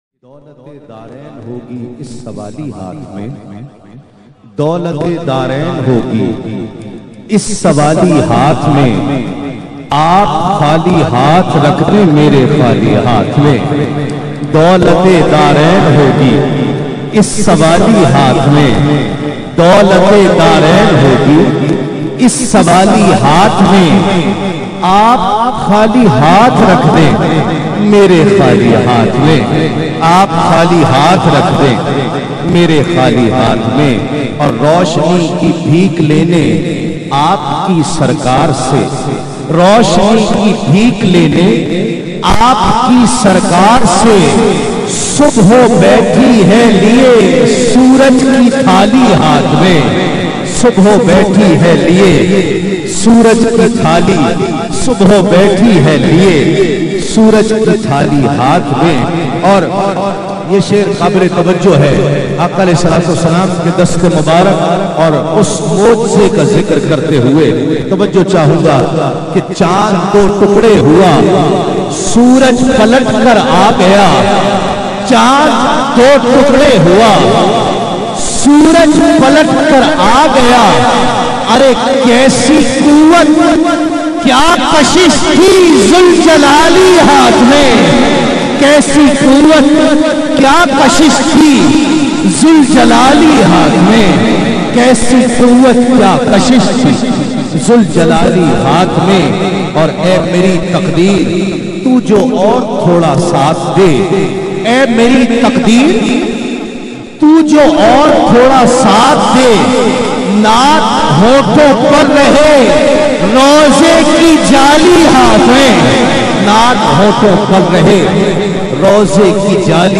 Naat in a Heart-Touching Voice